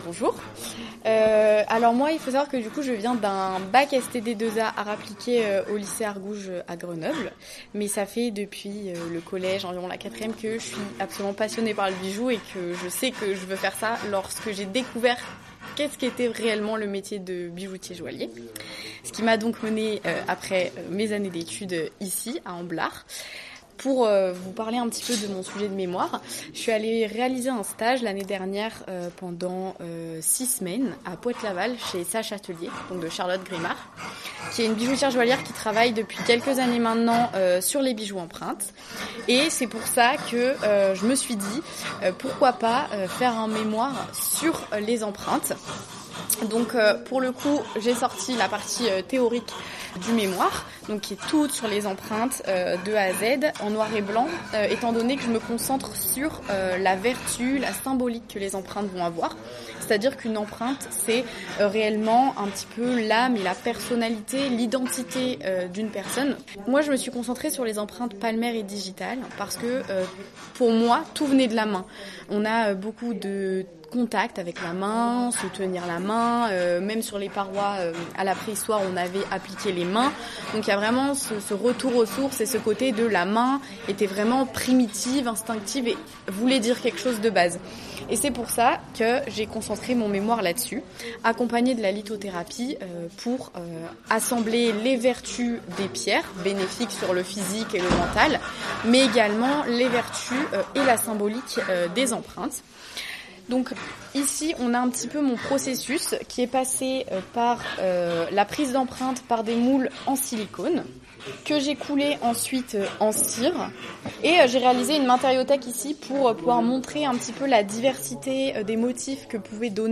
Afin de rendre compte de leur démarche, 7 étudiantes sur les 10 de cette 3e année sont interviewées dans le cadre de leur atelier et répondent chacune à 4 questions :
Un extrait de l’interview…